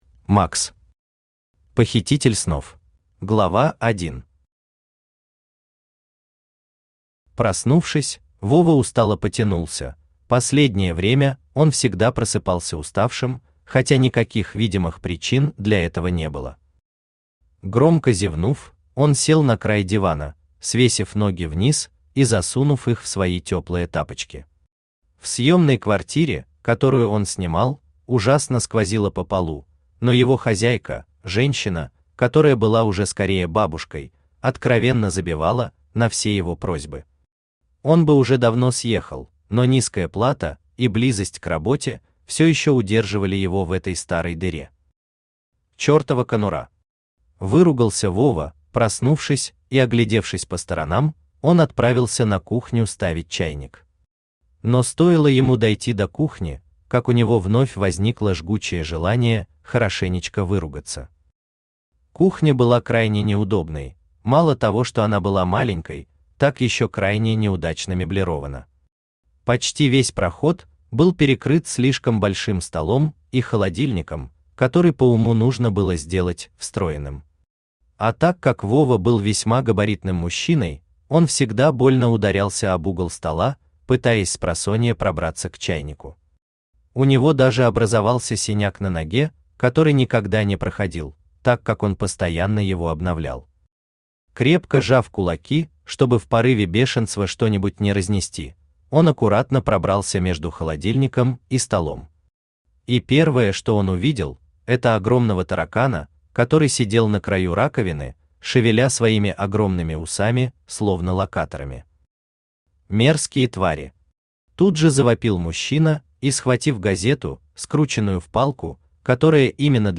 Aудиокнига Похититель снов Автор Макс Читает аудиокнигу Авточтец ЛитРес.